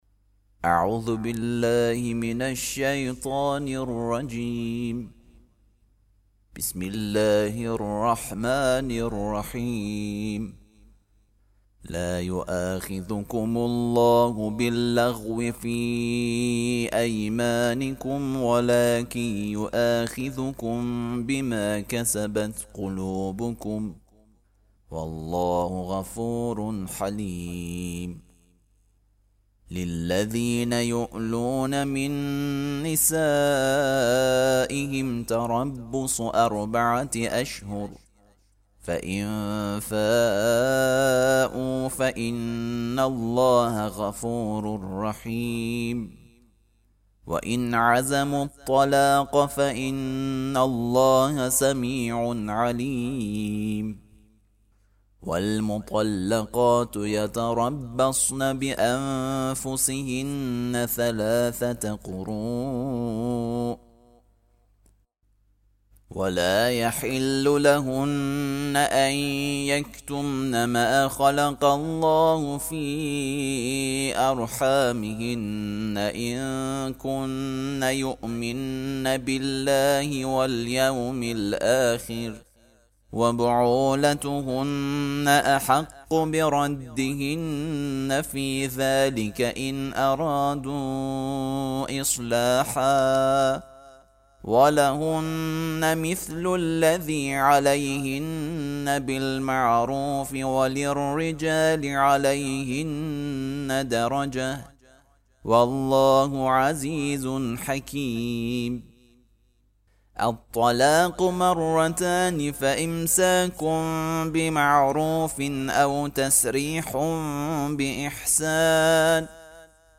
ترتیل صفحه ۳۶ سوره مبارکه بقره (جزء دوم)